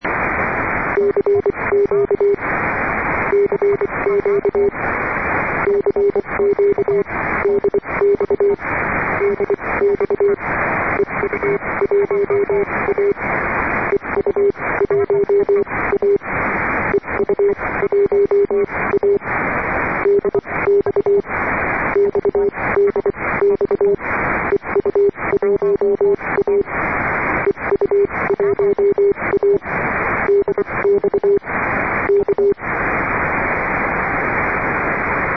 запись "помехи" на авиа в CW